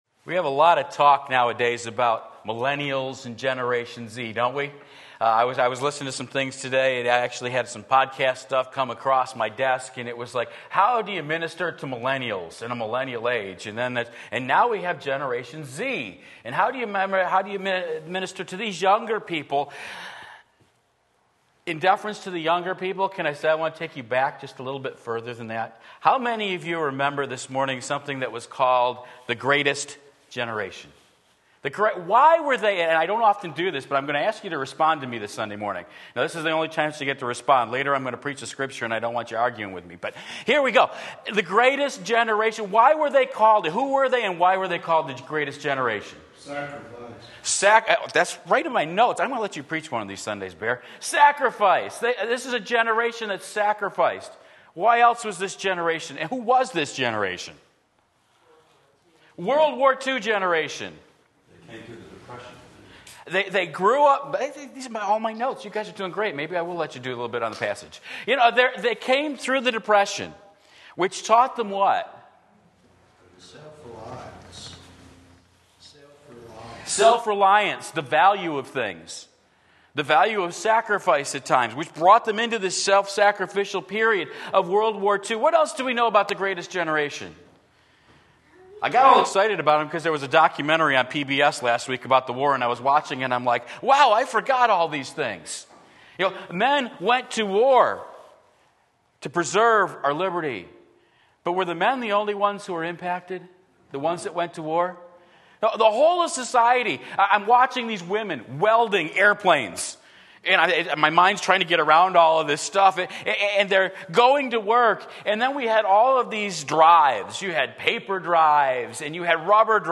Sermon Link
The Spirit of God Makes All the Difference Romans 8:7-13 Sunday Morning Service